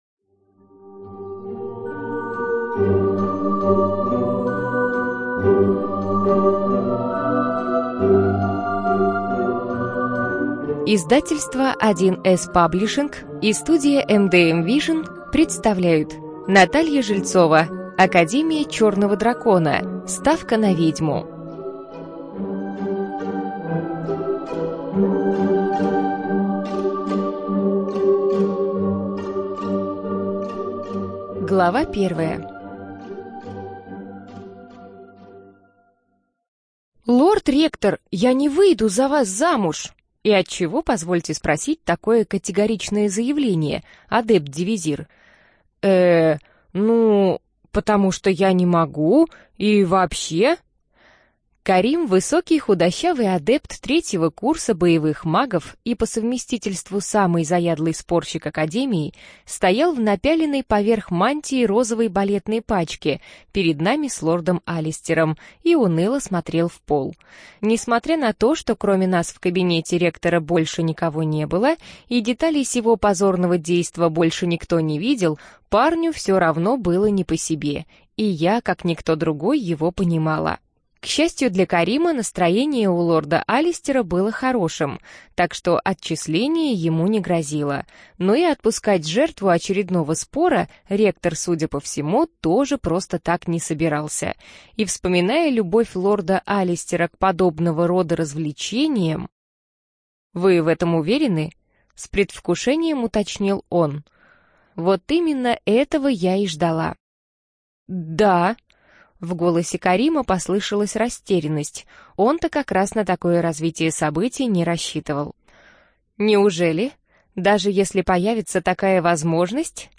Студия звукозаписи1С-Паблишинг